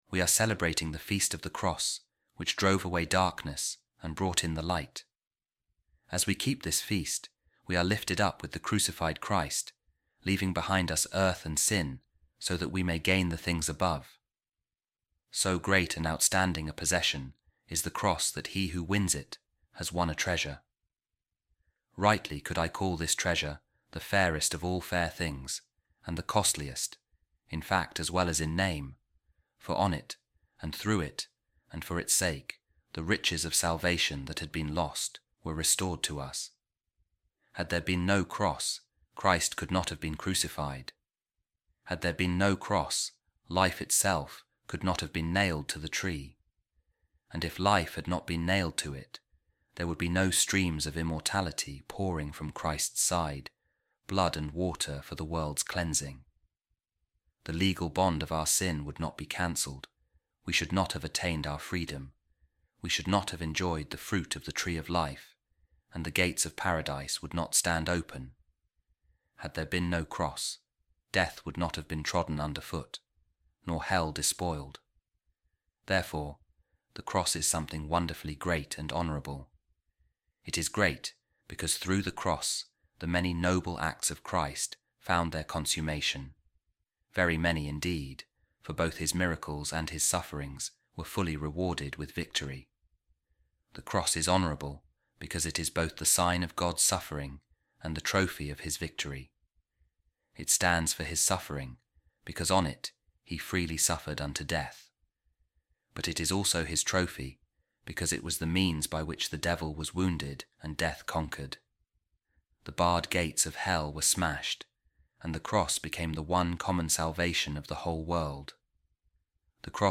A Reading From The Homilies Of Saint Andrew Of Crete | The Glory And Exaltation Of Christ Is The Cross